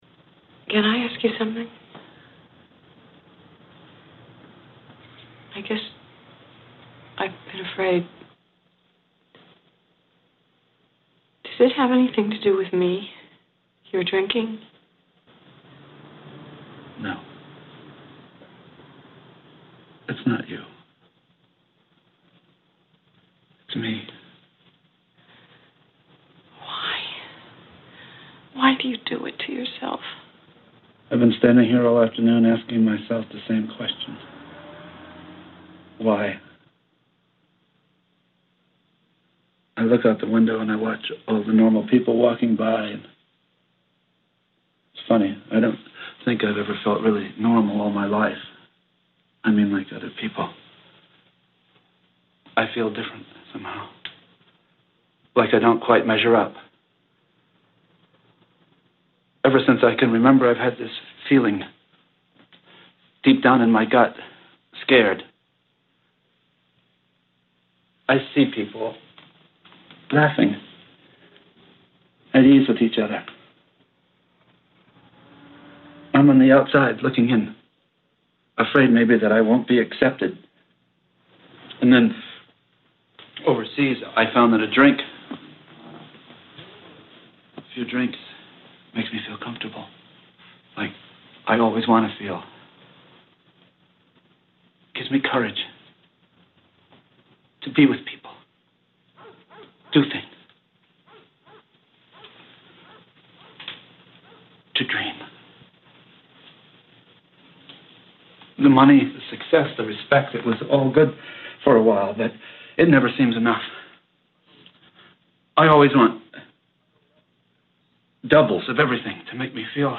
3.5 minute audio recording from a movie about Bill W, the founder of AA (the original 12 Step program). At this point in the movie, Bill is still a helpless drunk, and he is speaking to his wife about his addiction - just before he hits bottom and is committed to an asylum.
Bill W Speaking to his wife.mp3